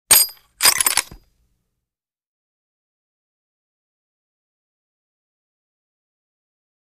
Loading Pistol, Bright